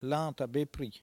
Langue Maraîchin
Patois - archive
Catégorie Locution